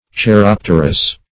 Search Result for " cheiropterous" : The Collaborative International Dictionary of English v.0.48: Cheiropterous \Chei*rop"ter*ous\, a. (Zool.) chiropterous; belonging to the Chiroptera, or Bat family.